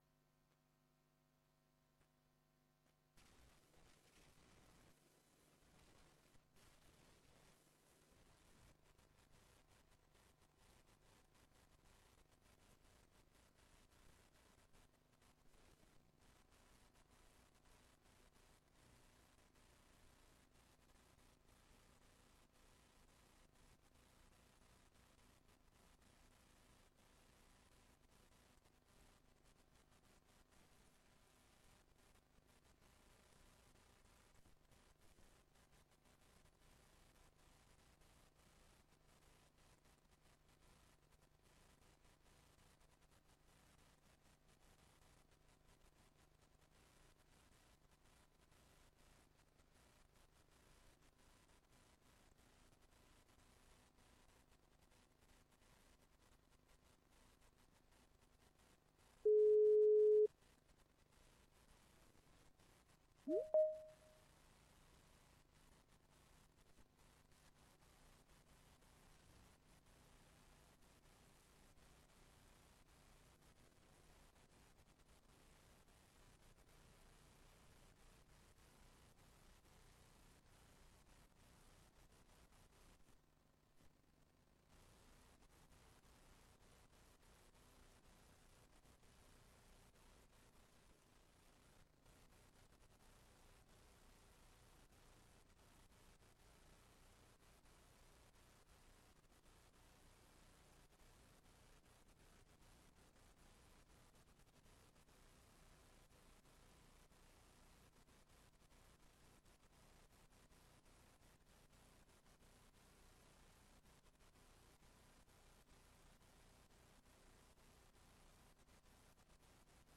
Raadsbijeenkomst 10 december 2024 20:00:00, Gemeente Tynaarlo
Locatie: Raadszaal